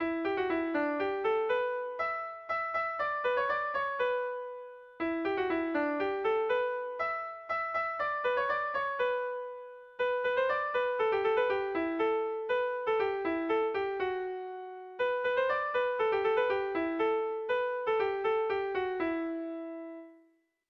Erromantzea
Euskal kopla klasikoa.
Kopla handiaren moldekoa